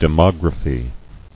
[de·mog·ra·phy]